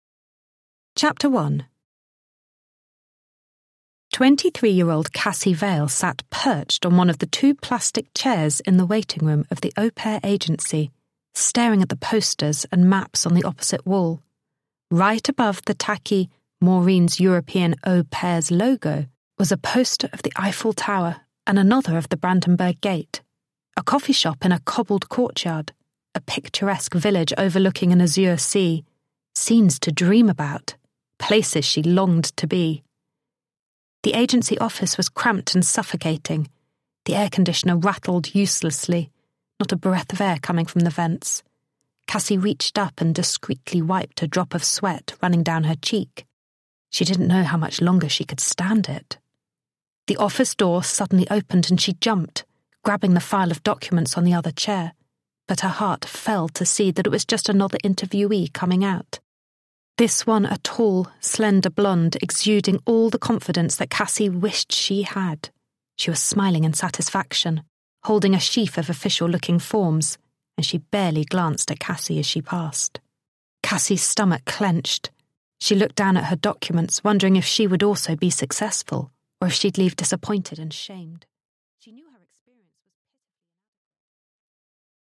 Аудиокнига Almost Gone | Библиотека аудиокниг